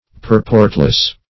Purportless \Pur"port*less\, a. Without purport or meaning.